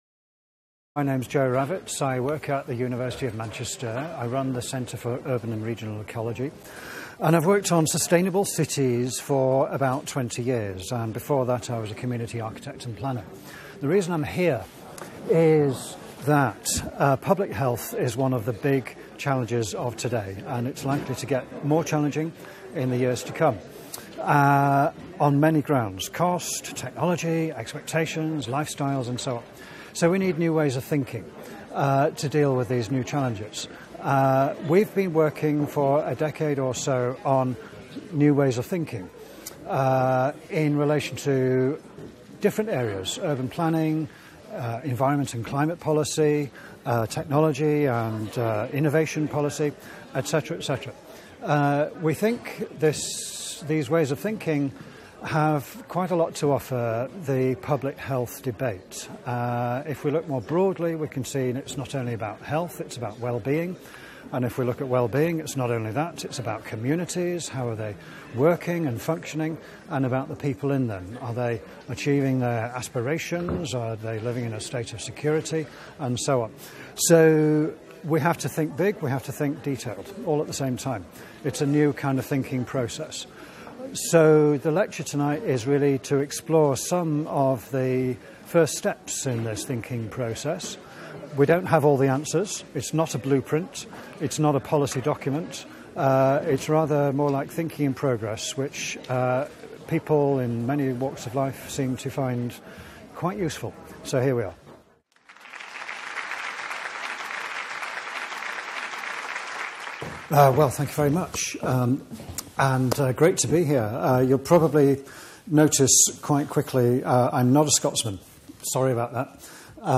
Seminar
Centre for Contemporary Arts (CCA), 350 Sauchiehall Street, Glasgow, G2 3JD, United Kingdom